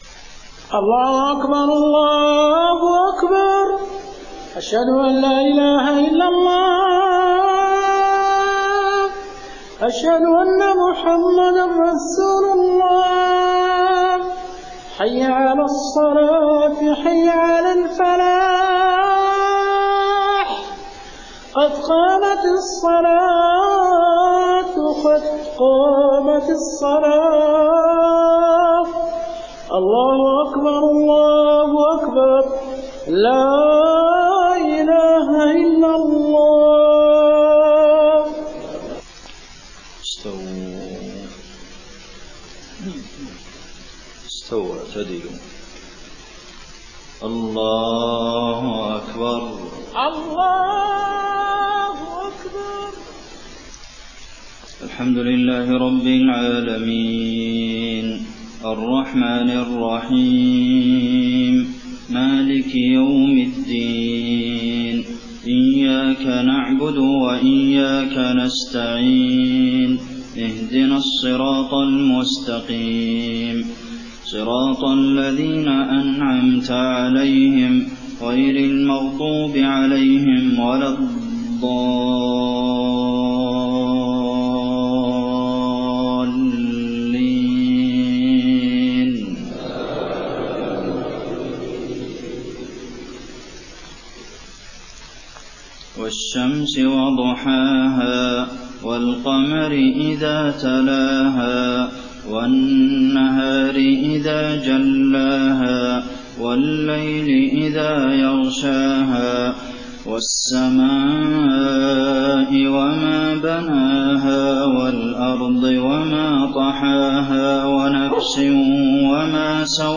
صلاة المغرب 1 صفر 1431هـ سورتي الشمس و التين > 1431 🕌 > الفروض - تلاوات الحرمين